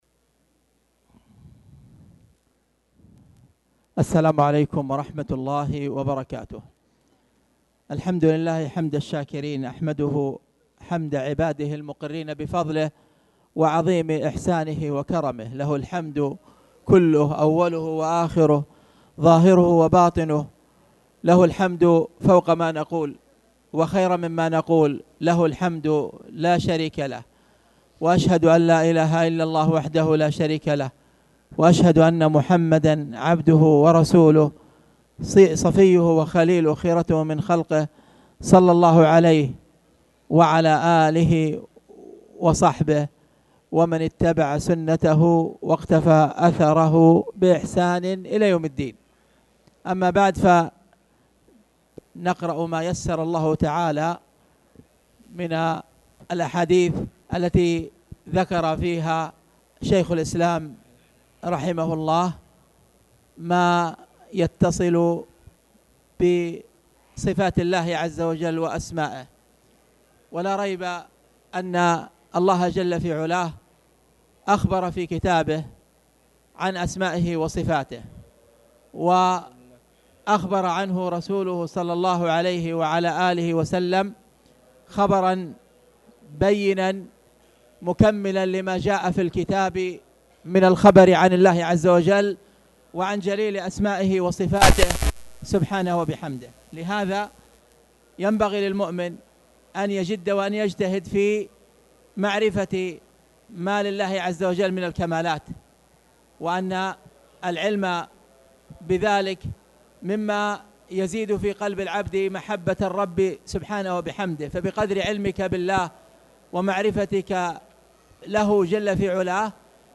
تاريخ النشر ٢٧ ربيع الثاني ١٤٣٩ هـ المكان: المسجد الحرام الشيخ